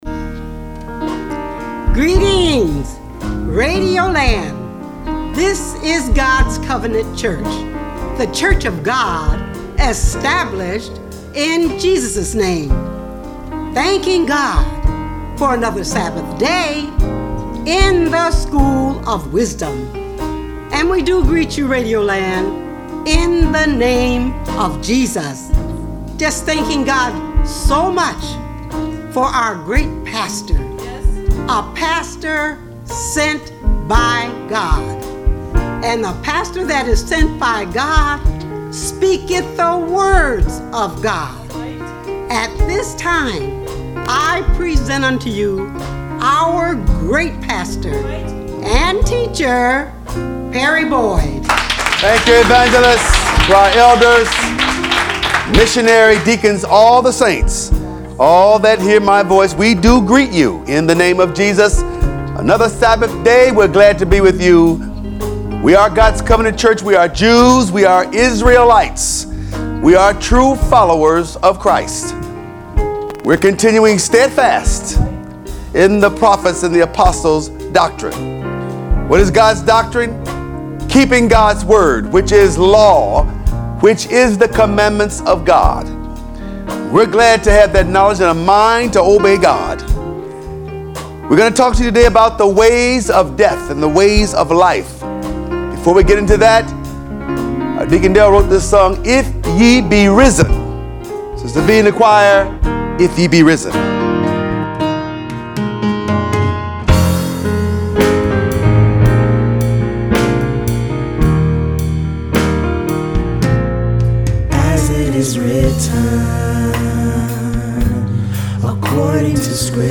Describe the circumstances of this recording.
Broadcast-384.mp3